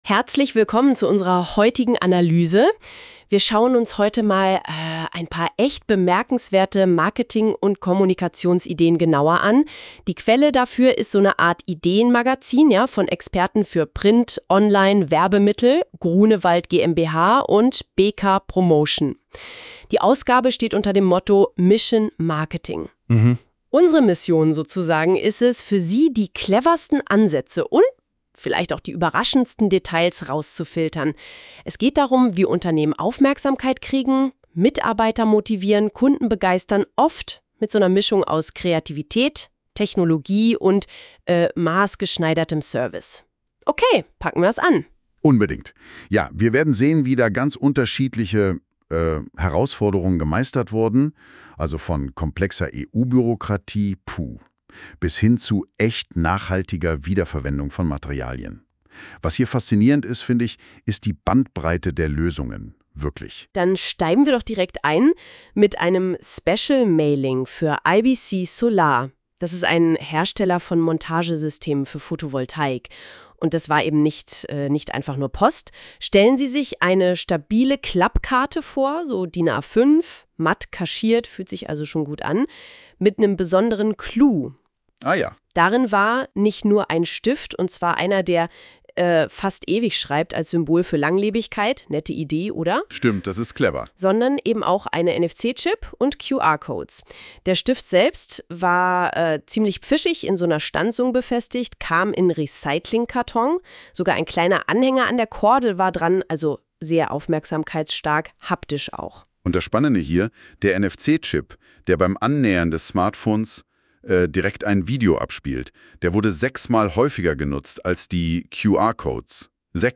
(Podcast erstellt mit KI-Unterstützung)